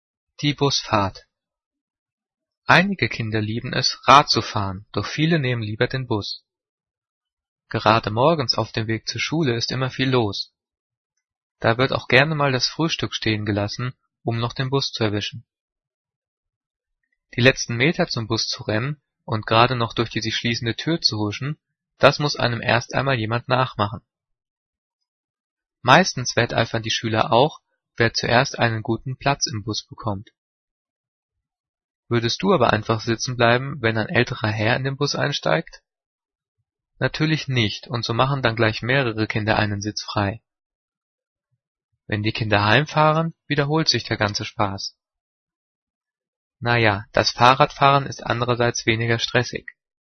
Gelesen: